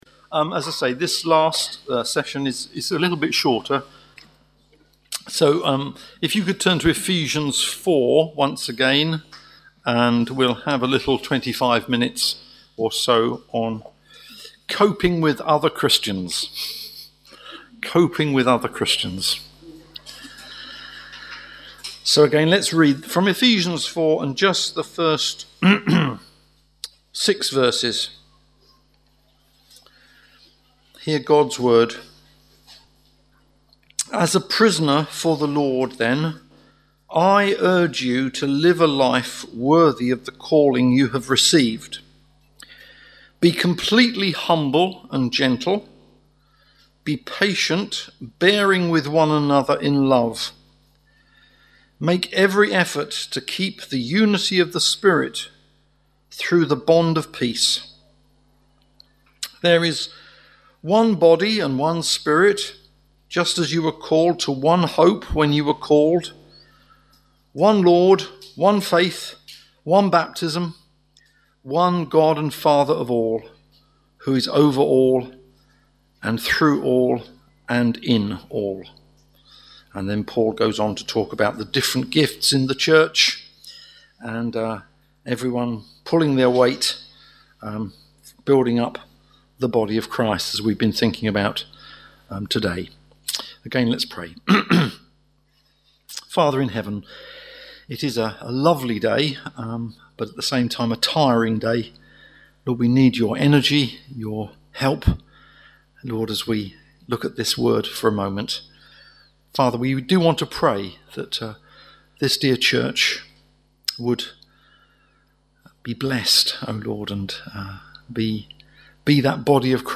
Church Family Day Away Service Type: Family Day « Day Away